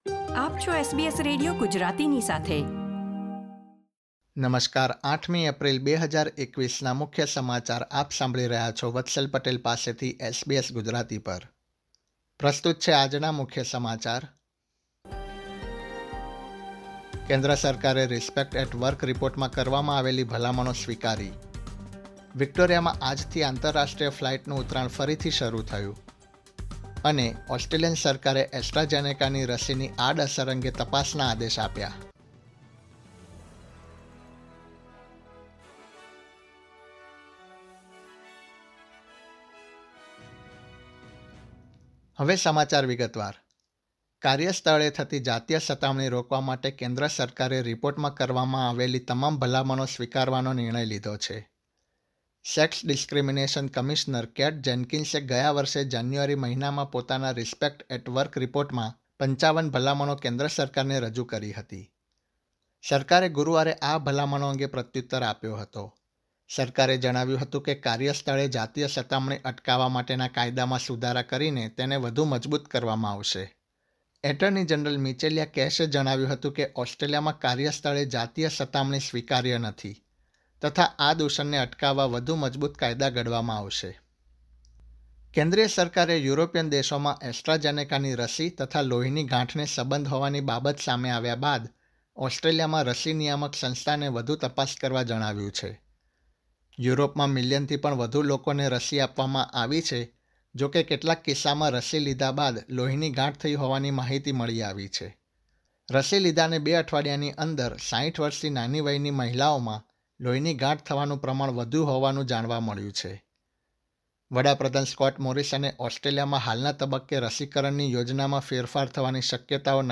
SBS Gujarati News Bulletin 8 April 2021
gujarati_0804_newsbulletin.mp3